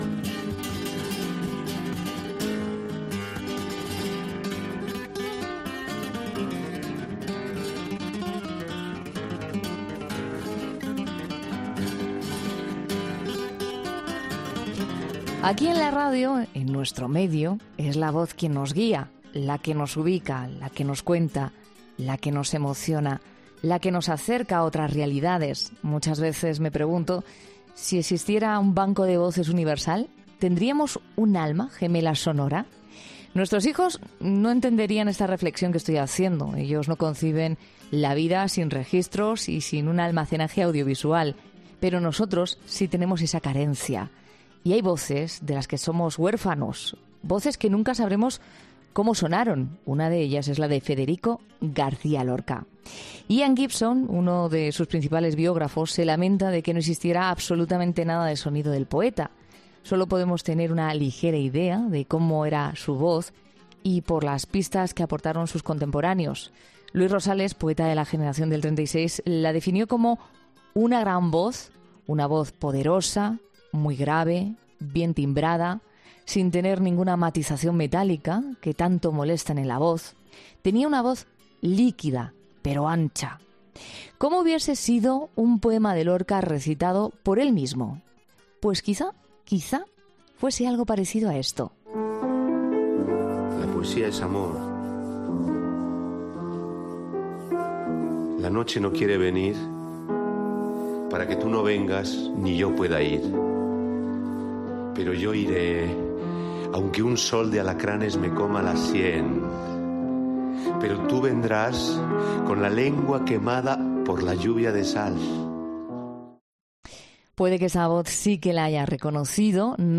El actor ha pasado por 'La Noche' de COPE donde ha hablado de sus inicios con la obra, de la figura de Lorca y de los reconocimientos que ha logrado a lo largo de su carrera, entre otros asuntos.